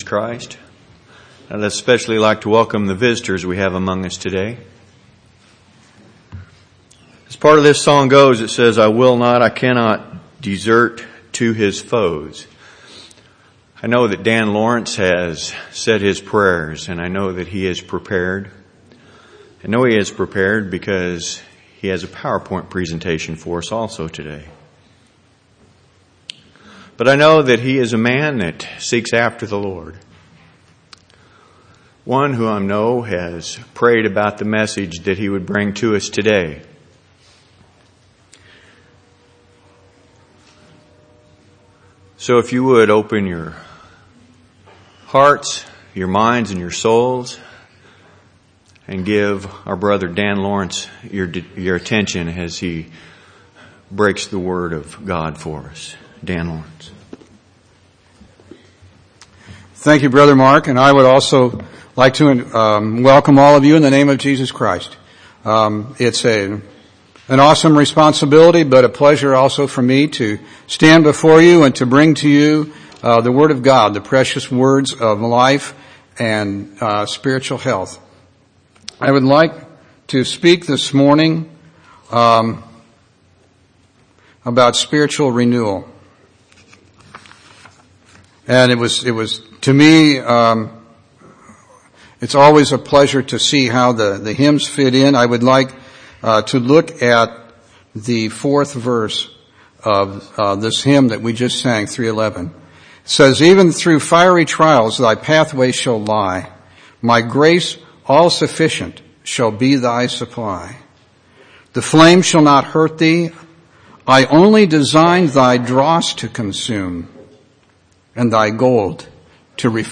10/11/2009 Location: Temple Lot Local Event